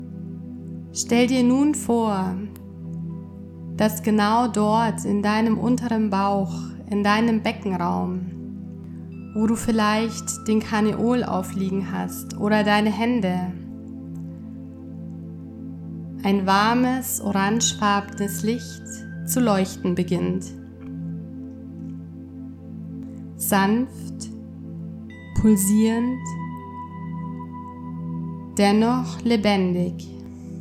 Diese gesprochene Sakralchakra-Meditation begleitet dich auf eine sanfte Reise in deinen Körper, zu deinen Gefühlen und zurück in deinen kreativen Fluss.
• Format: Geführte Meditationen (Audio-Datei)
• mit Musik unterlegt